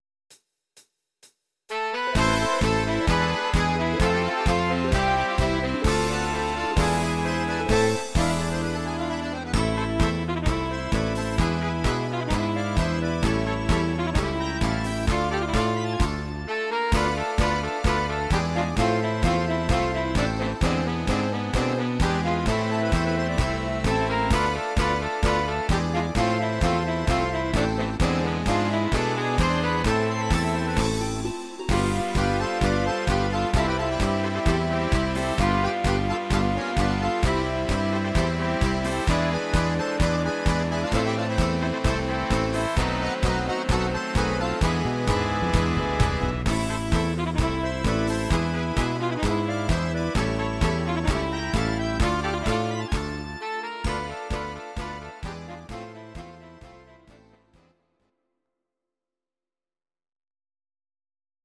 These are MP3 versions of our MIDI file catalogue.
Orchester